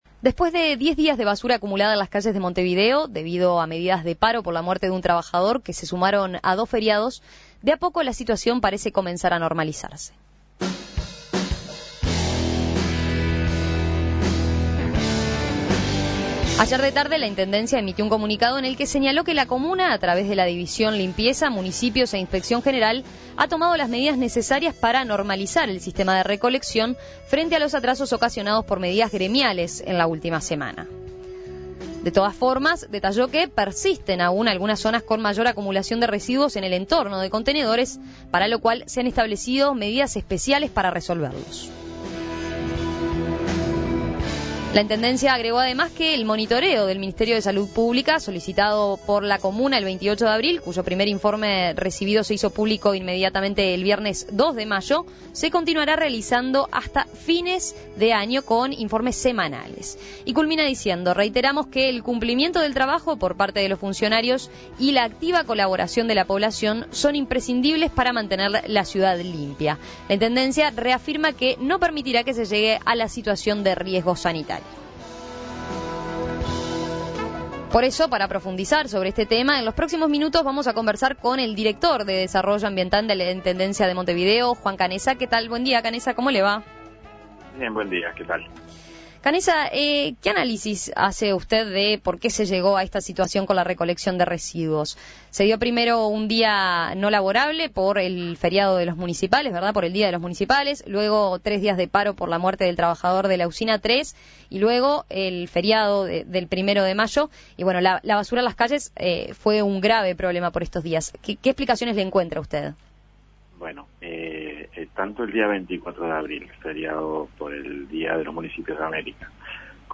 En diálogo con En Perspetiva, el director de Desarrollo Ambiental de la comuna, Juan Canessa, dijo que desde dicha área las tareas de limpieza estaban "claramente previstas".